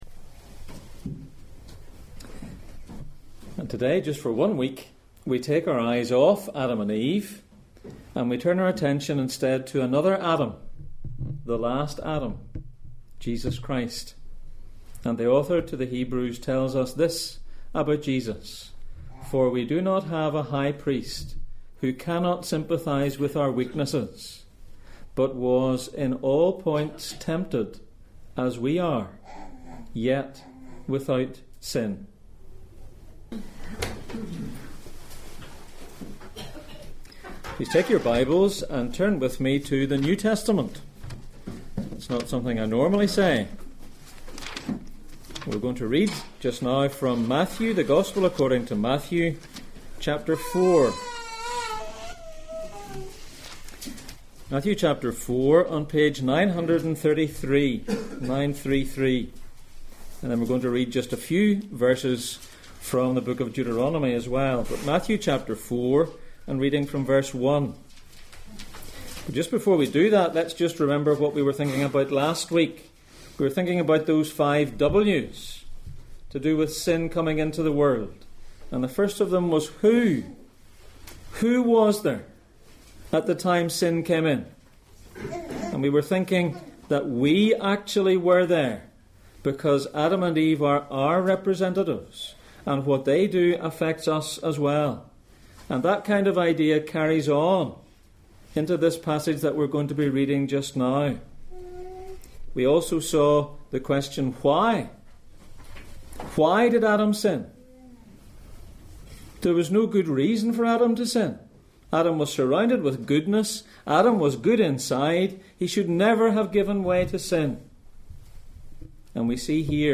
Back to the beginning Passage: Matthew 4:1-11, Deuteronomy 8:1-3, Psalm 91:11-12 Service Type: Sunday Morning